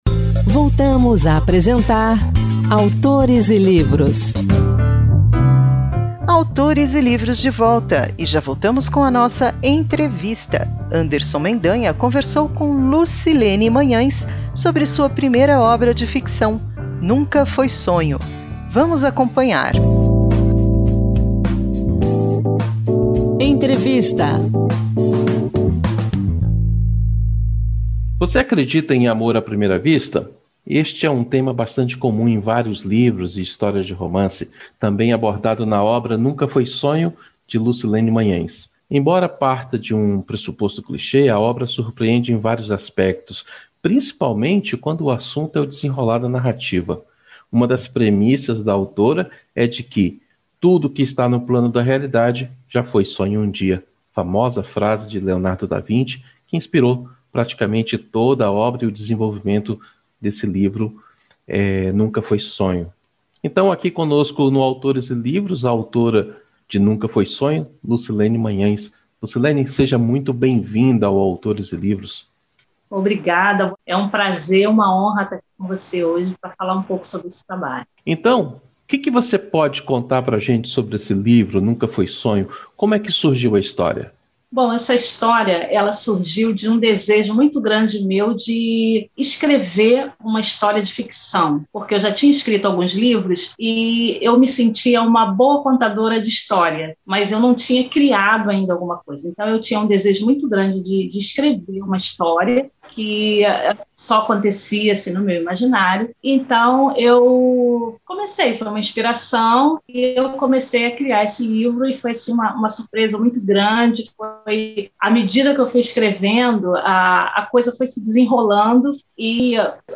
O Autores e Livros dessa semana entrevista